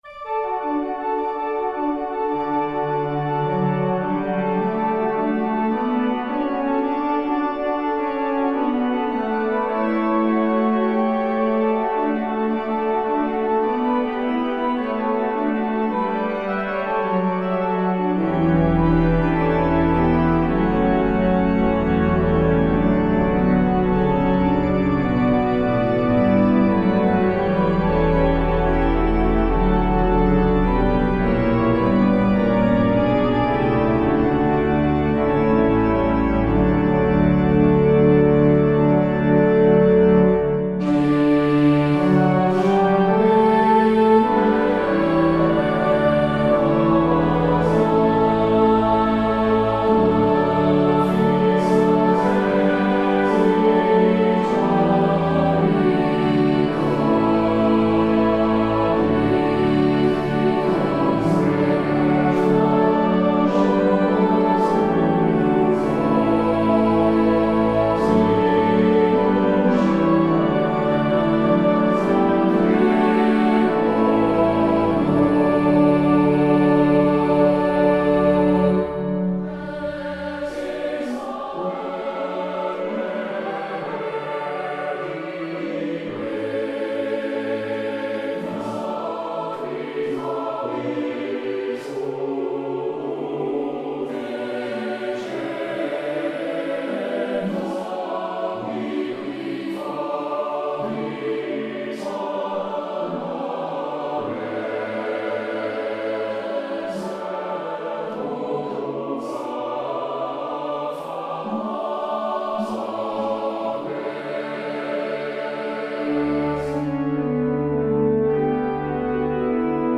Free descant and harmonization to the hymn tune DUKE STREET